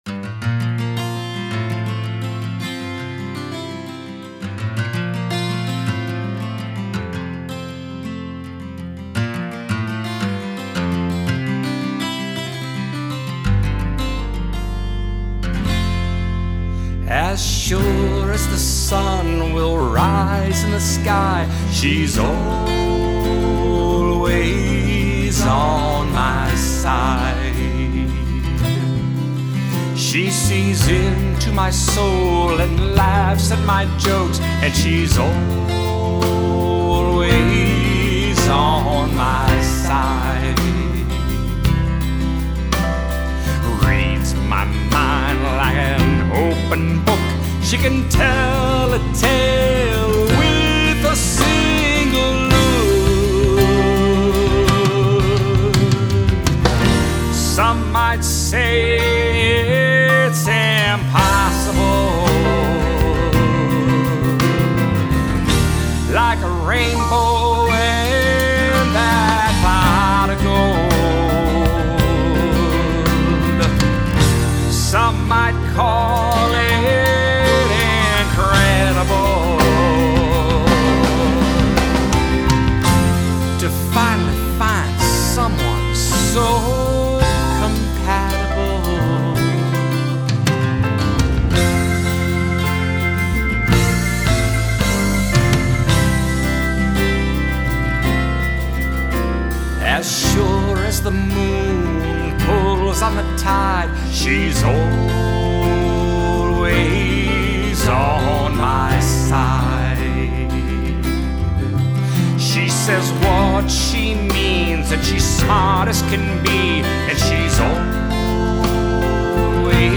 lead vocals
Rhythm guitar
Back up vocals